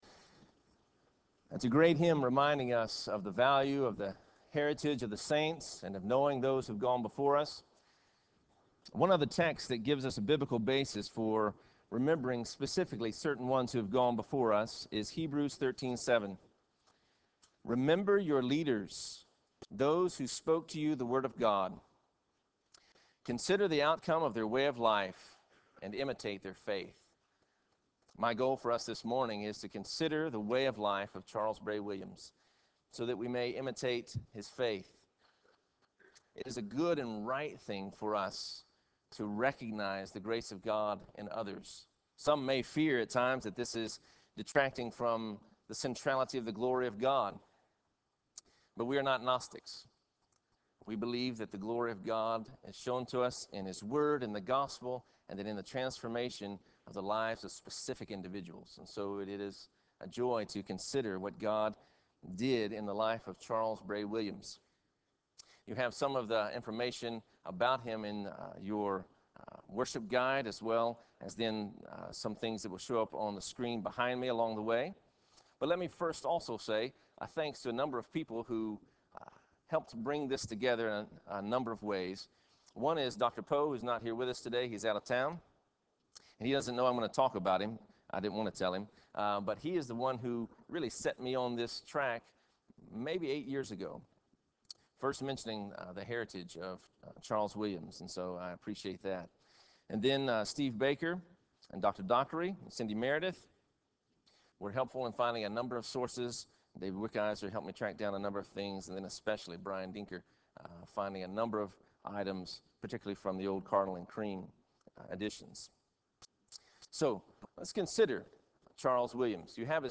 Founders Day Chapel